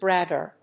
/ˈbrægɝ(米国英語), ˈbrægɜ:(英国英語)/
bragger.mp3